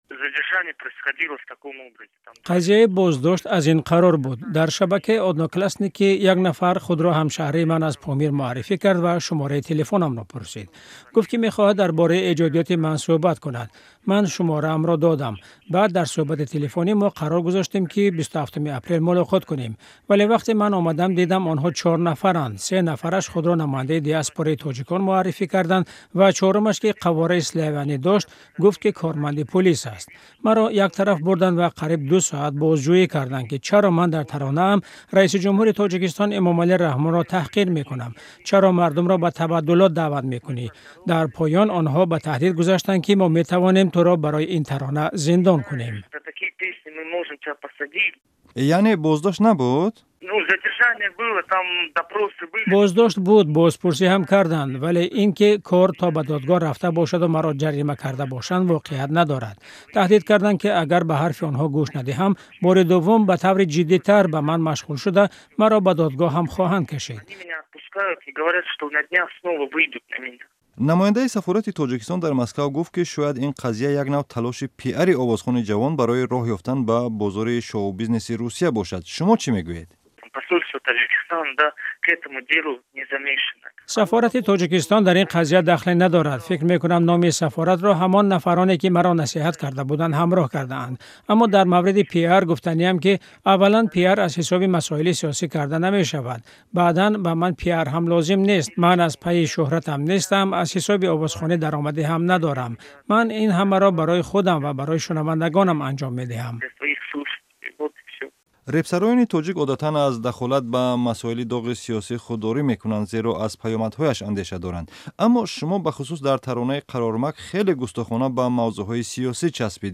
Гуфтугӯи ихтисосӣ